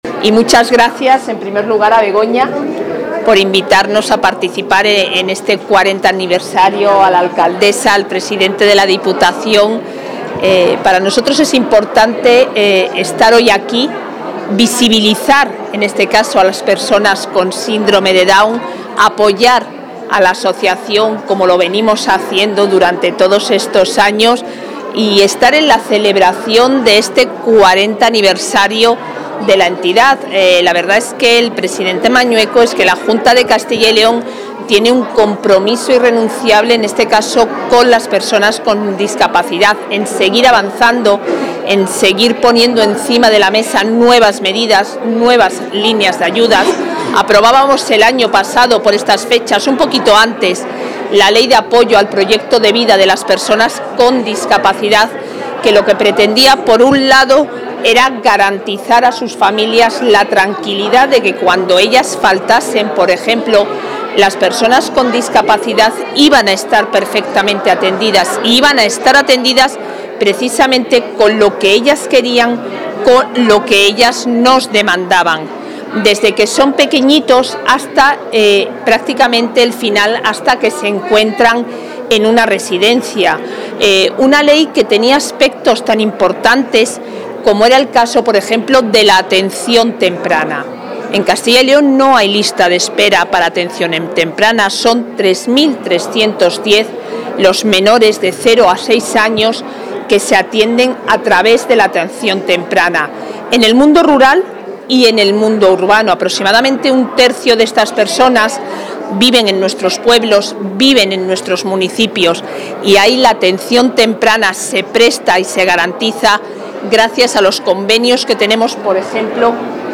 Intervención de la vicepresidenta de la Junta.
La vicepresidenta y consejera de Familia e Igualdad de Oportunidades recoge un galardón durante la ceremonia de los 40 años de la Asociación Síndrome de Down Burgos por la estrecha colaboración entre el Ejecutivo autonómico y la entidad.